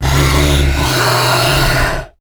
controller_idle_0.ogg